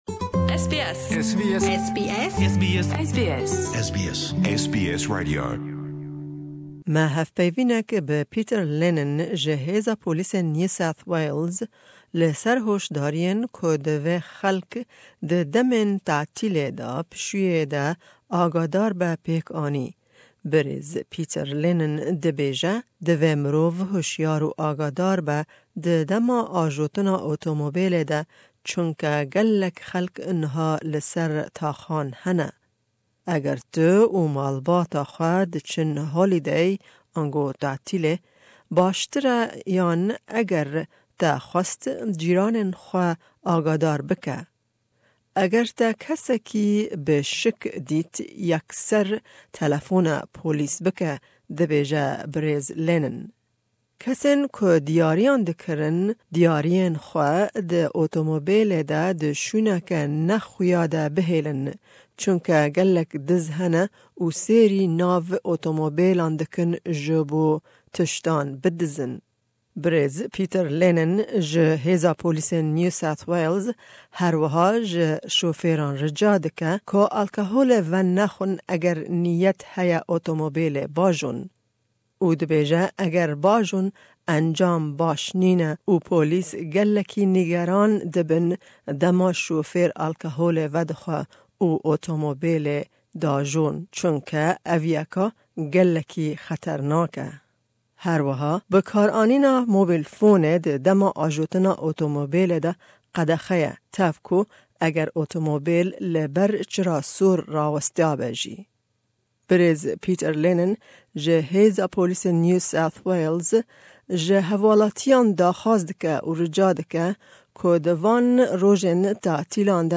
Me hevpeyvîneke bi zimanî Înglîzî